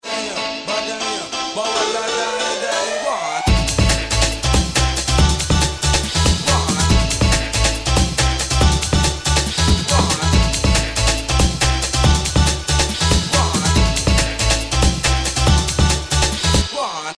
• Category Old Skool